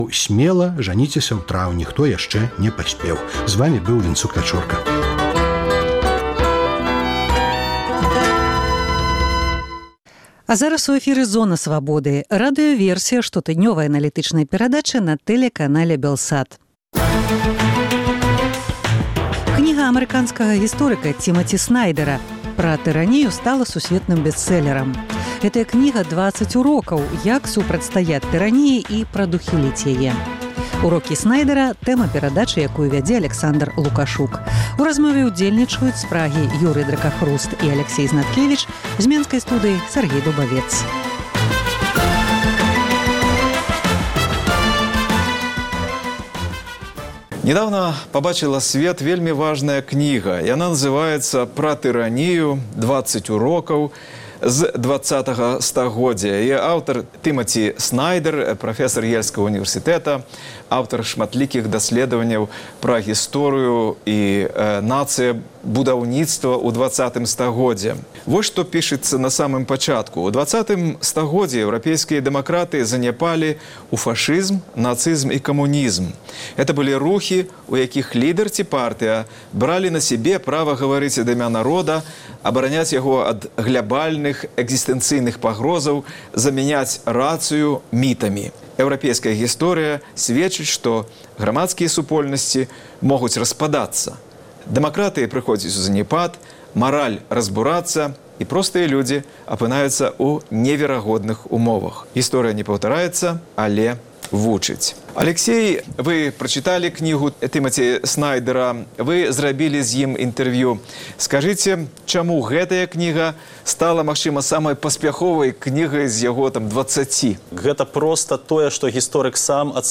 з менскай студыі